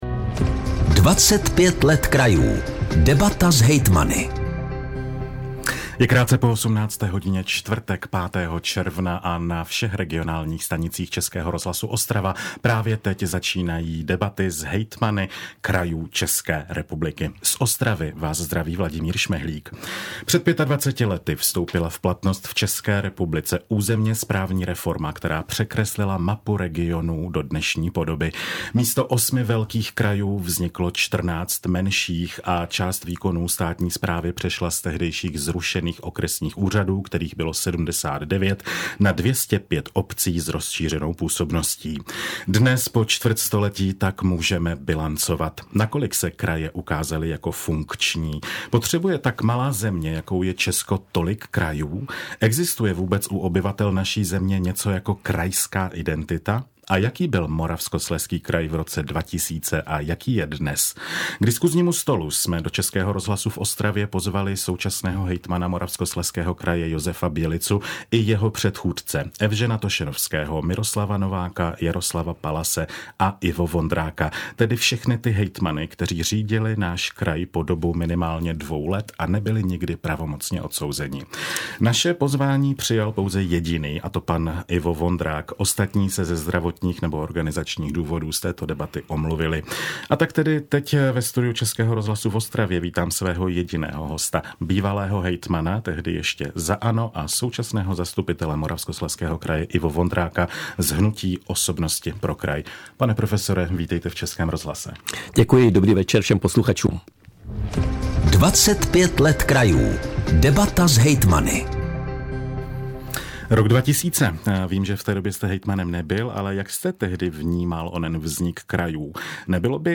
Zprávy ČRo Ostrava: Debata s hejtmany - 05.06.2025